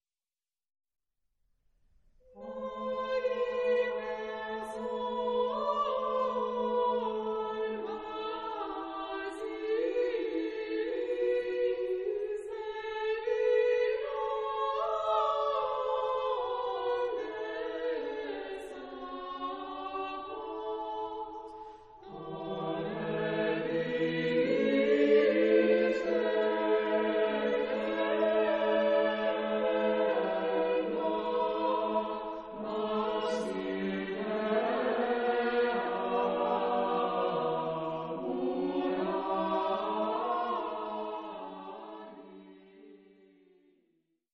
Christmas Music from the Byzantine Tradition